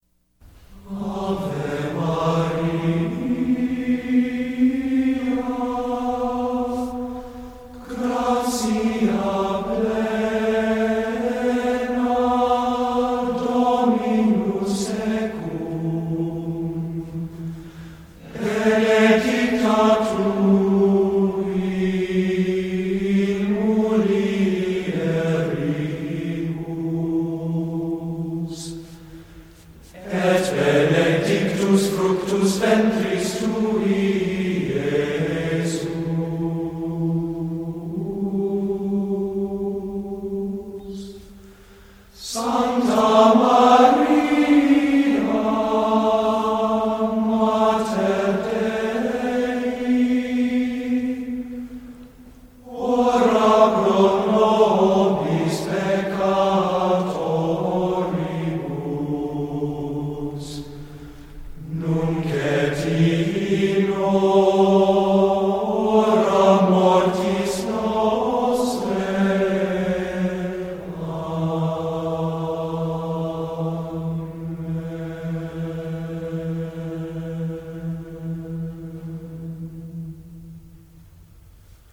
recitar
Sonidos: Textos literarios